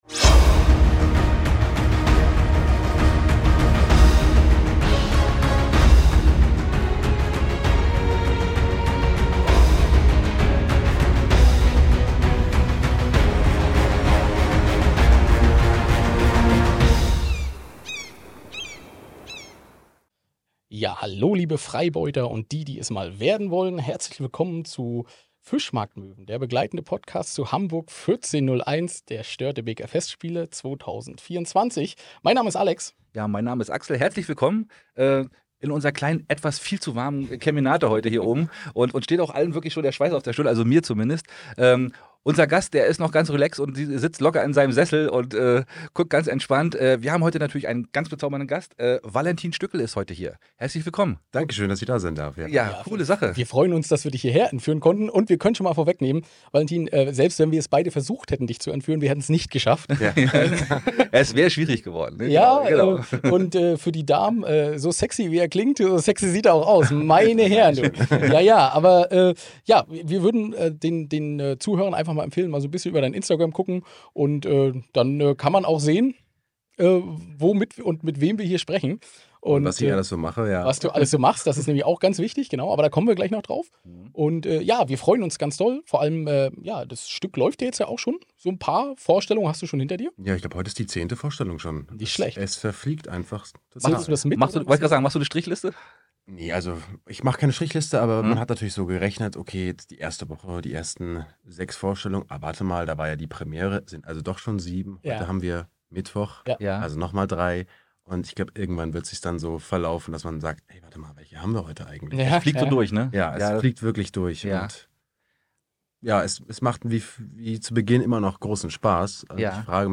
Viel Spaß mit unserem heutigen Interview.